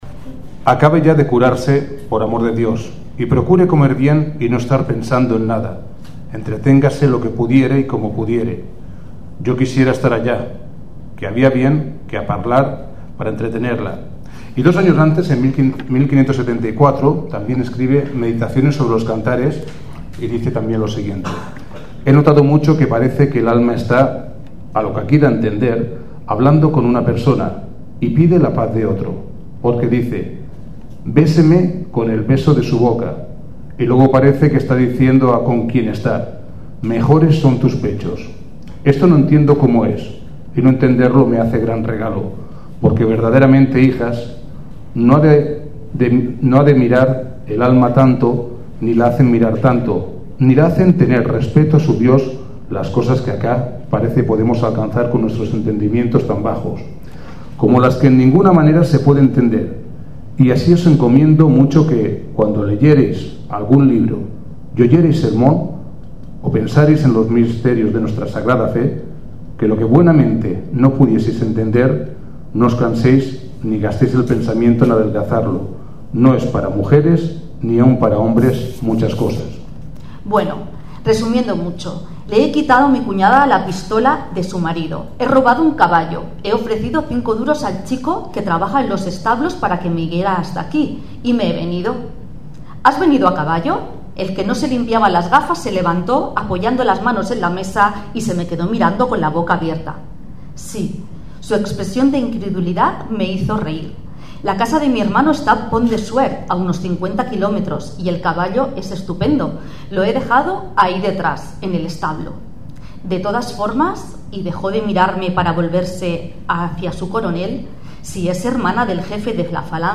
El acto ha consistido en la lectura de textos pertenecientes a la selección realizada por la escritora Marta Sanz cuya temática este año ha sido “El placer, la alegría y la risa de las mujeres.
Las personas encargadas de las lecturas han sido integrantes del Club de Lectura.